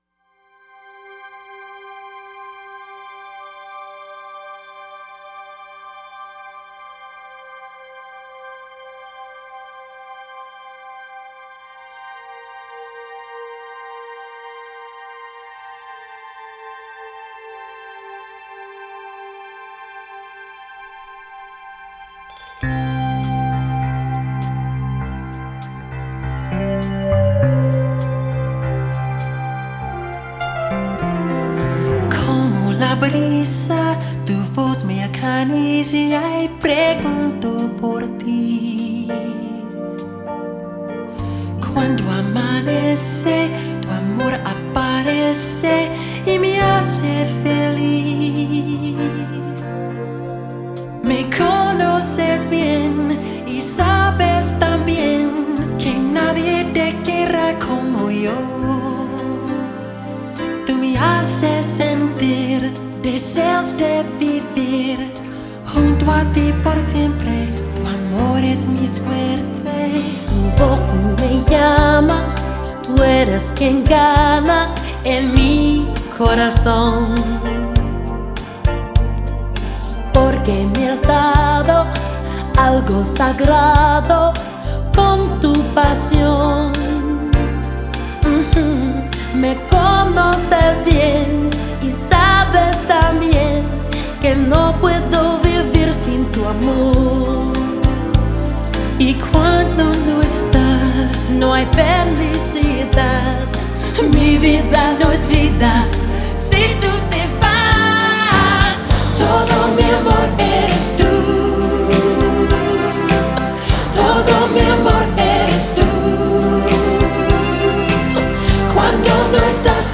没错，虽然编曲一点没变，但用西班牙语唱出来却又是另一种完全不同的感觉。